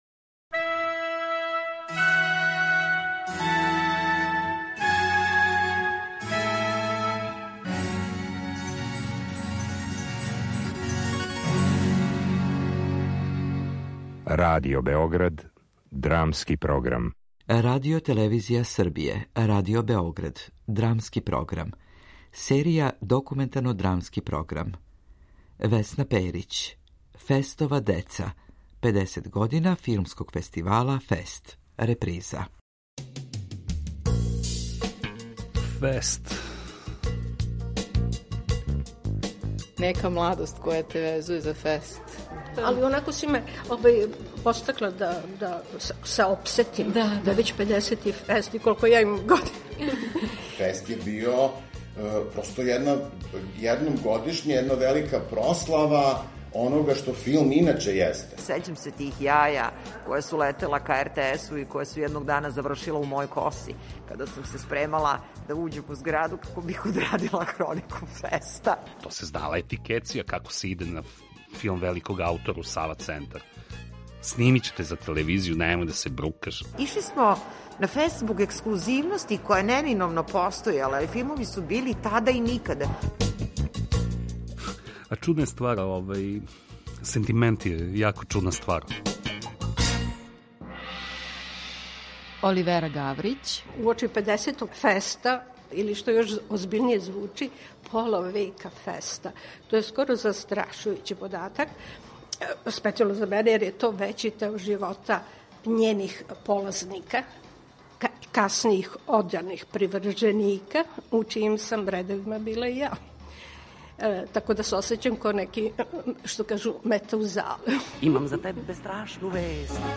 Документарно-драмски програм